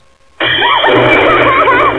arclaugh70.wav